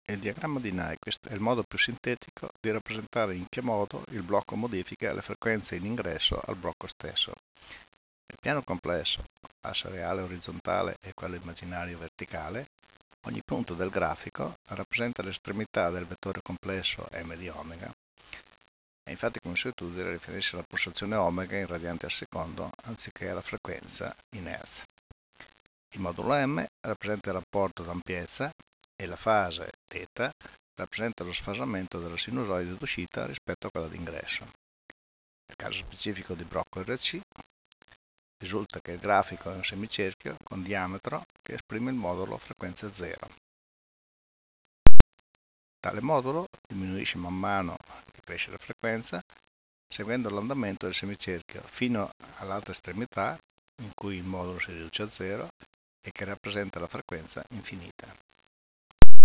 [commento audio]